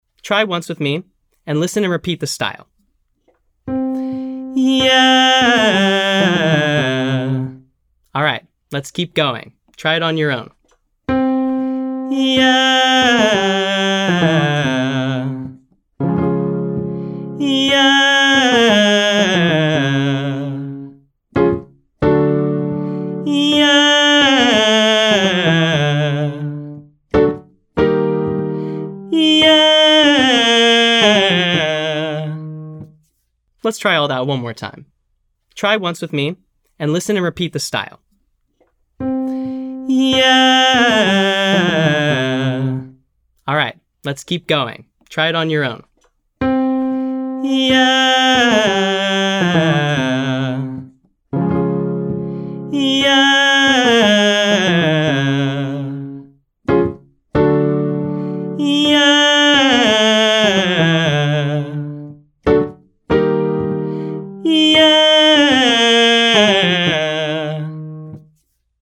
From: Pop Daily Practice for Low Voices
Exercise: Riff/run agility: 8-765, 5-4321 on Yah
Notice, we’re sustaining the first pitch and “running” over the next pitches that follow.